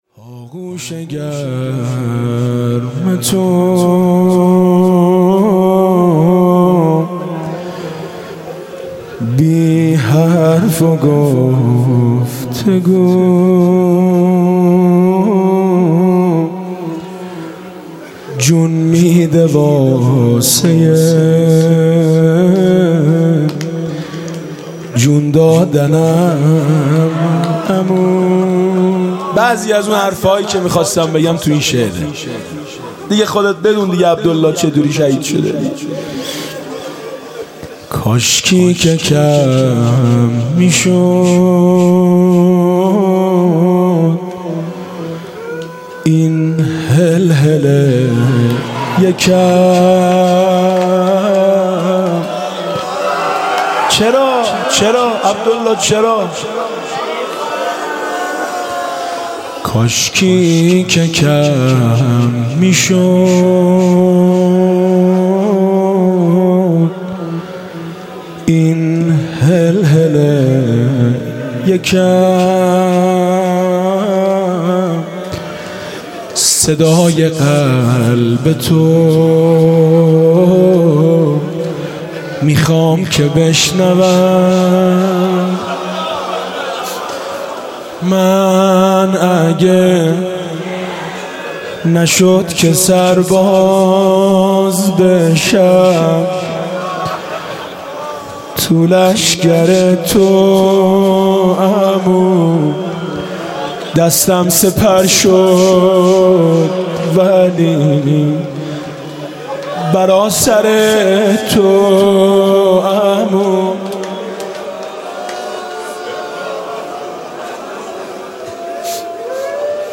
Shab5Moharram1394%5B02%5D.mp3